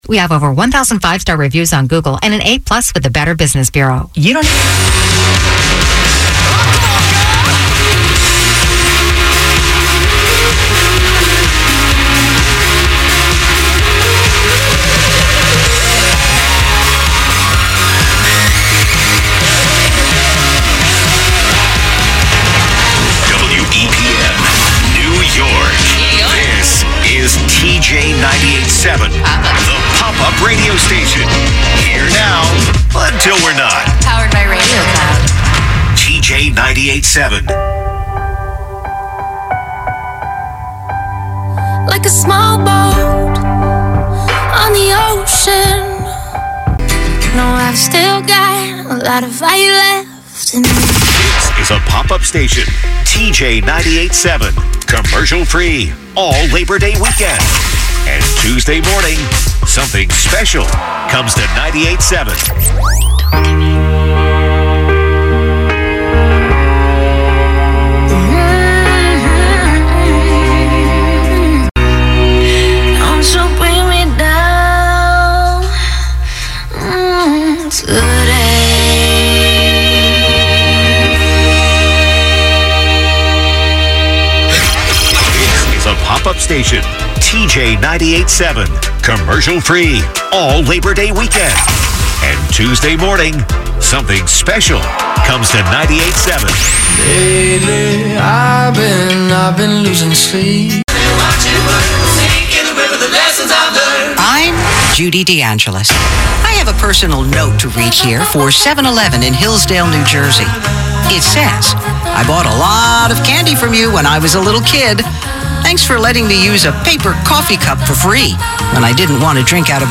New Format: Hot AC “TJ 98.7